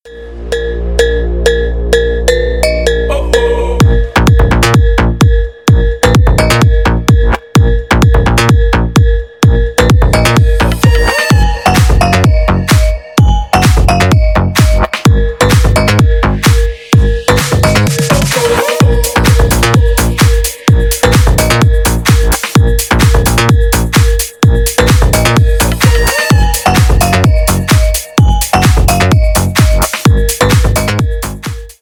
Ремикс
клубные # без слов